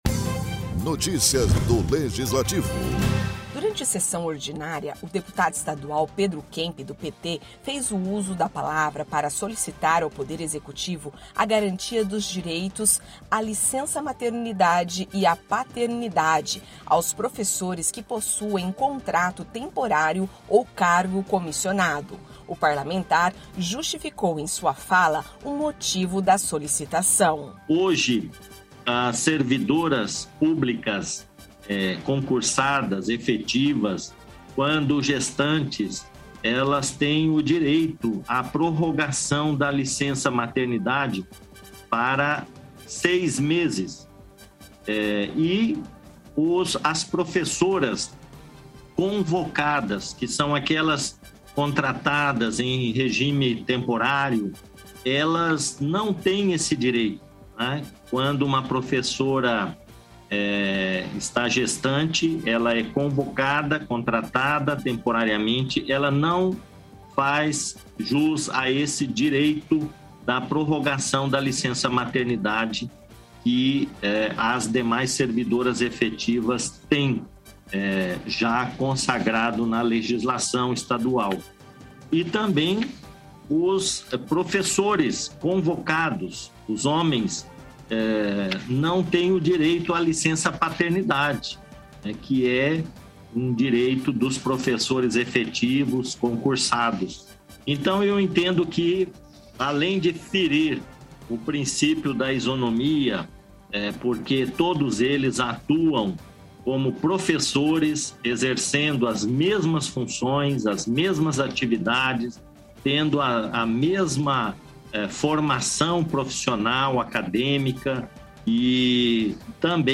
Durante sessão ordinária, o deputado estadual Pedro Kemp (PT) fez o uso da palavra para solicitar ao Poder Executivo a garantia dos direito a licença maternidade e paternidade aos professores que possuem contrato temporário ou cargo comissionado. O parlamentar justificou em sua fala o motivo da solicitação.